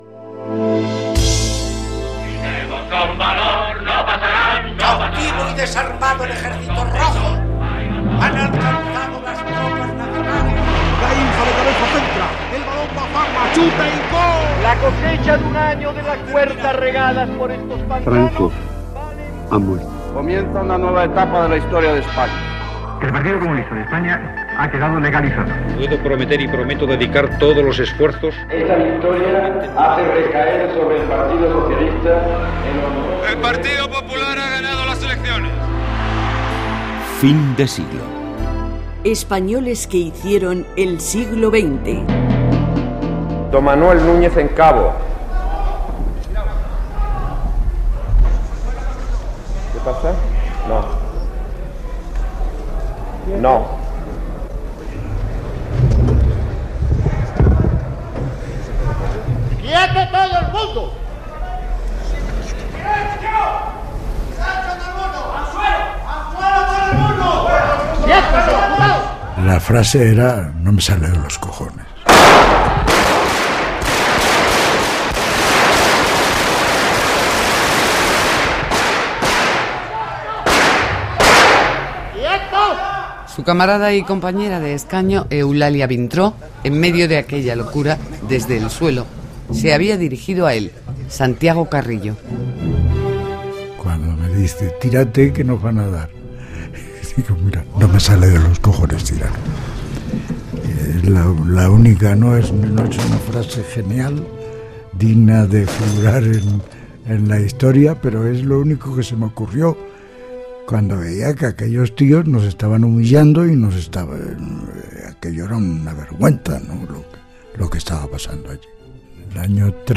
Careta del programa i fragment d'una entrevista al polític del Partitdo Comunista de España, Santiago Carrillo.